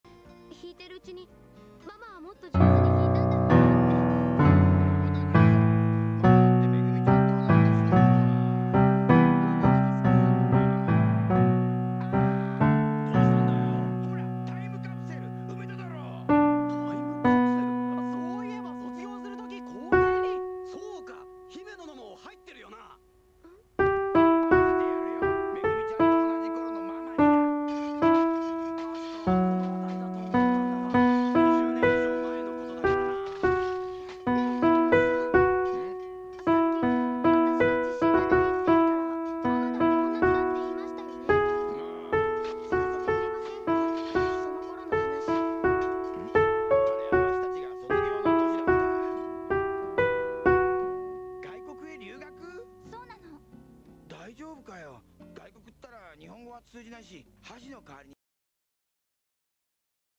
若干、吹奏楽の影響を受けている曲ですね。